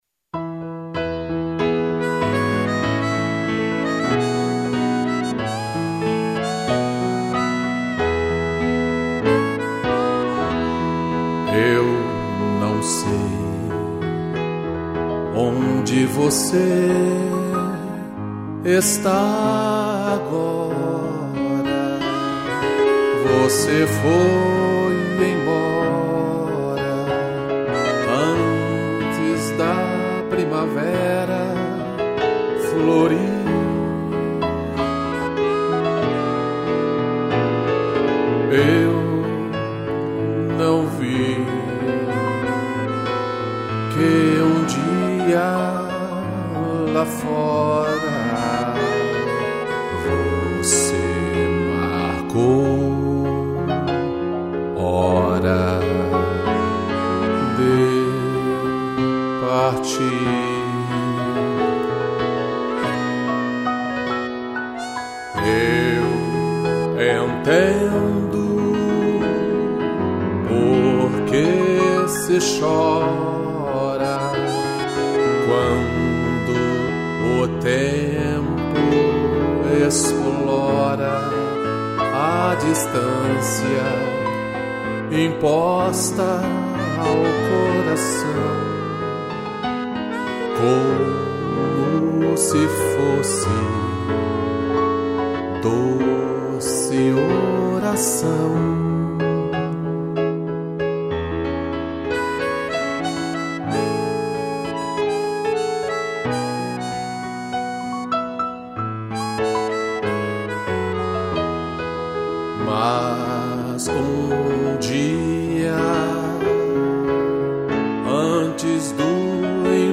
piano e gaita